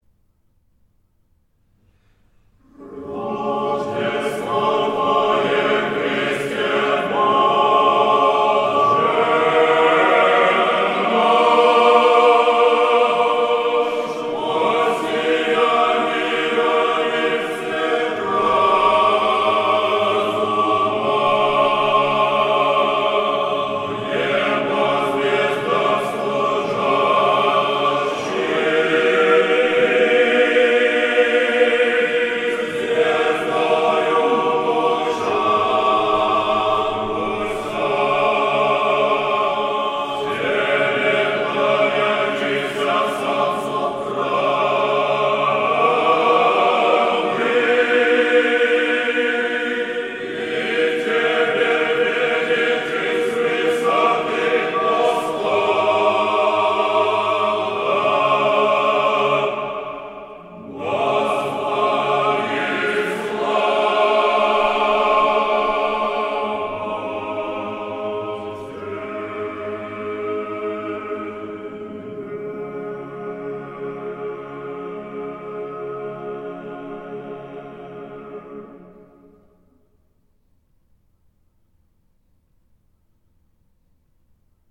Aудиокнига Концерт памяти Сергея Алексеевича Жарова Автор Данилов монастырь.